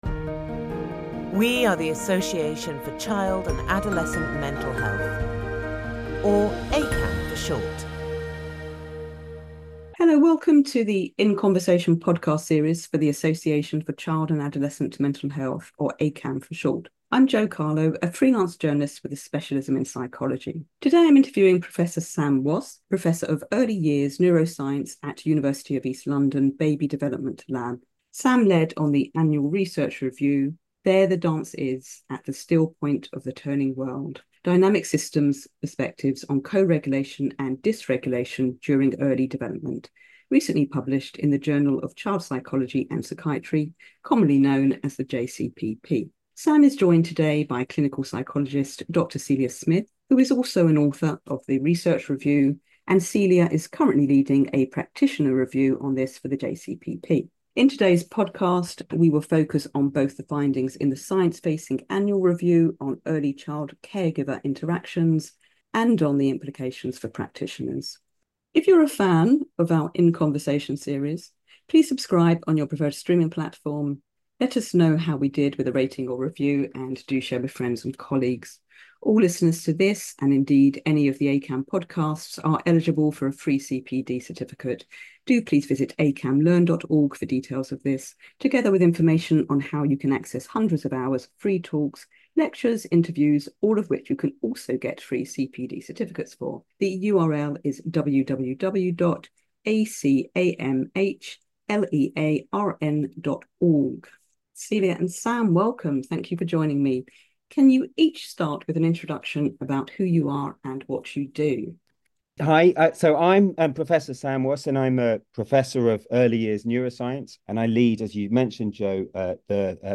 In Conversation podcast